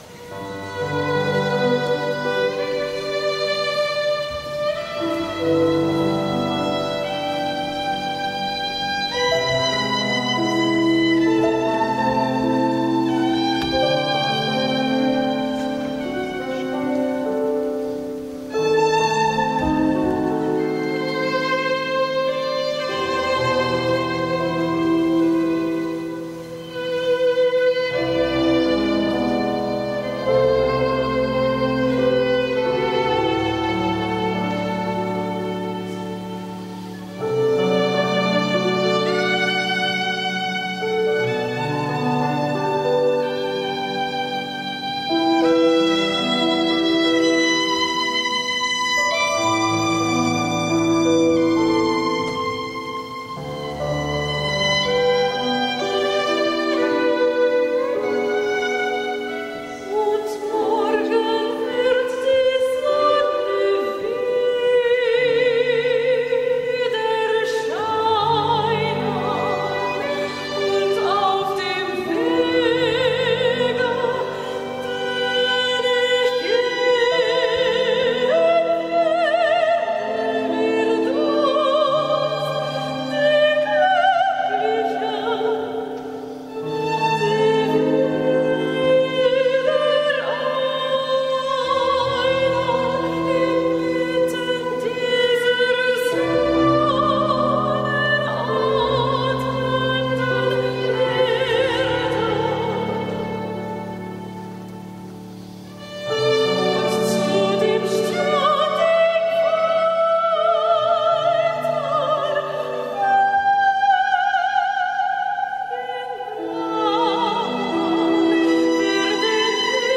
Soprane
Violon - Bruno Monsaingeon
Piano